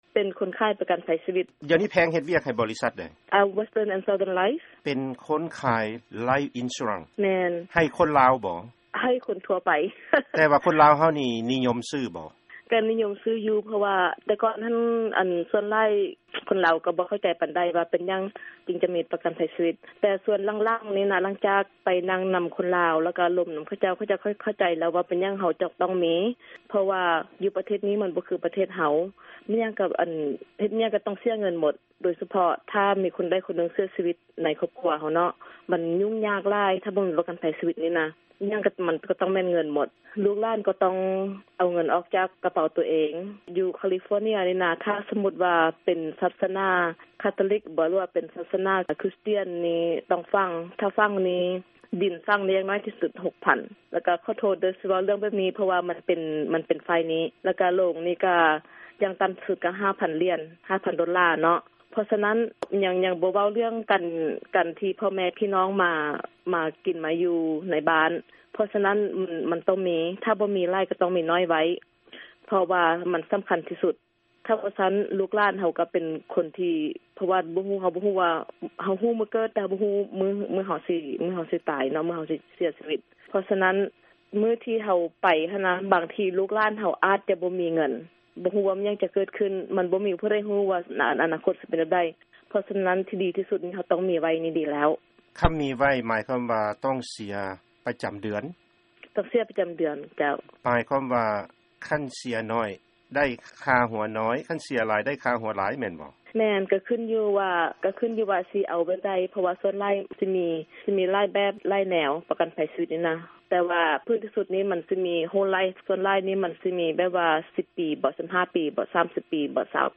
ສຳພາດ